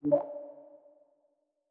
start_game.wav